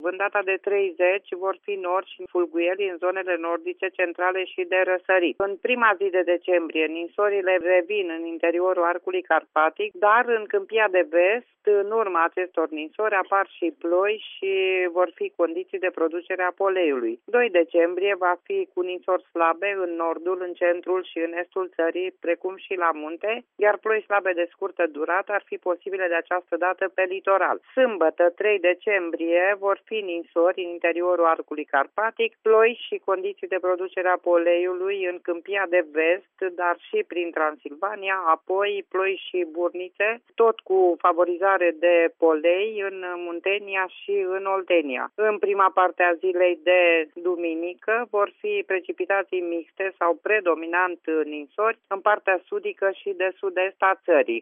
Pentru minivacanţa de săptămâna aceasta trebuie să ne pregătim haine groase, spune, pentru Radio România Actualităţi, meteorologul